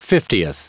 Prononciation du mot fiftieth en anglais (fichier audio)
Prononciation du mot : fiftieth